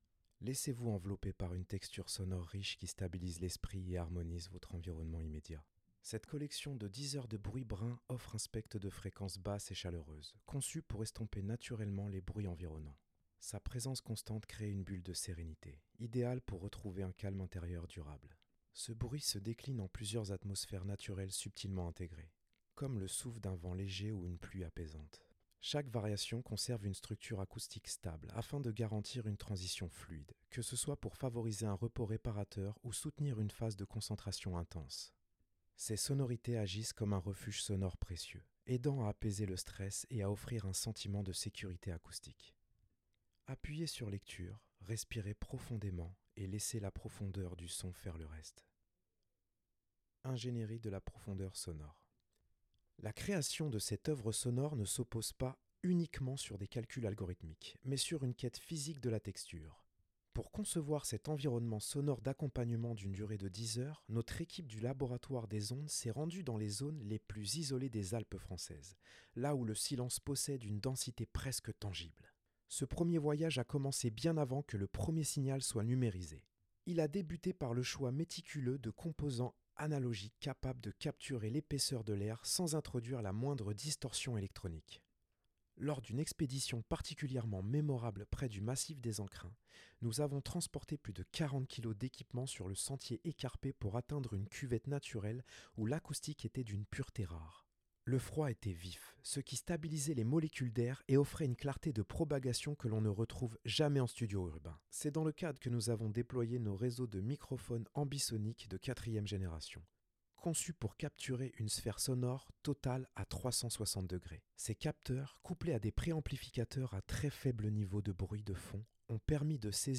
bruit brun